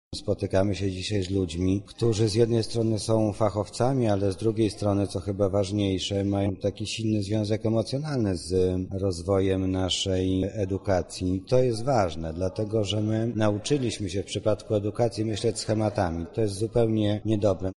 Mówi Mariusz Banach, zastępca prezydenta miasta